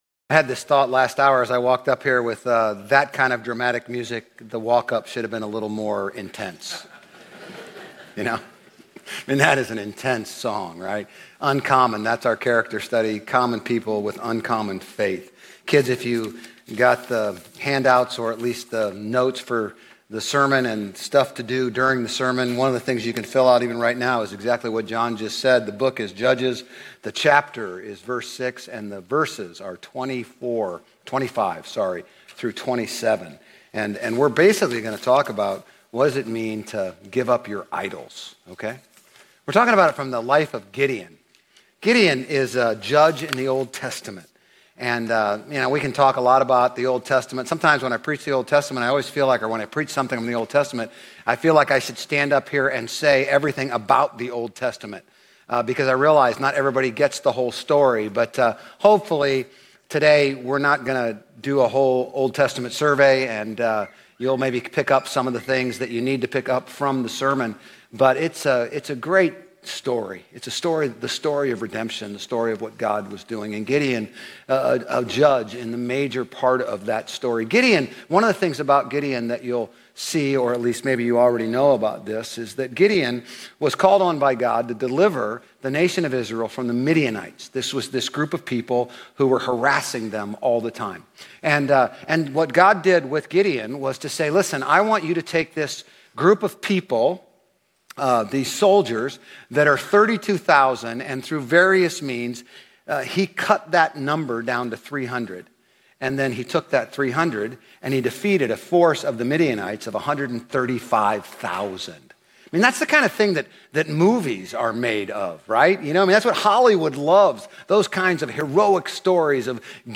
Grace Community Church Old Jacksonville Campus Sermons 7_20 Old Jacksonville Campus Jul 21 2025 | 00:36:13 1x 00:00 / 00:36:13 Subscribe Share RSS Feed Share Link Embed